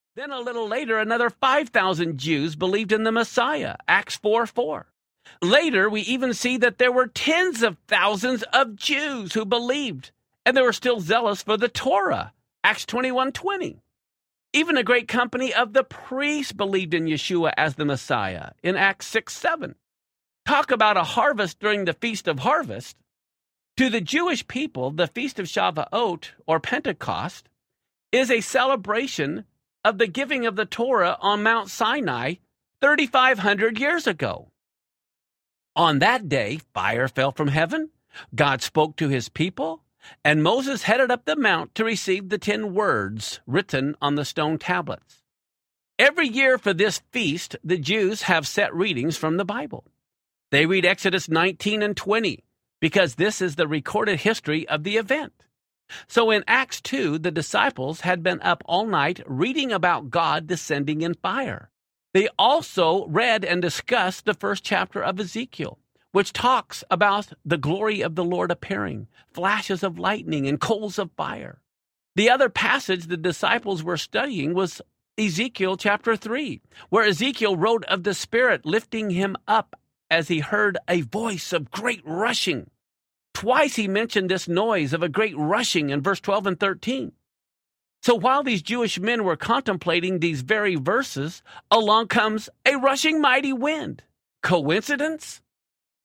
God’s Day Timer Audiobook
4.75 Hrs. – Unabridged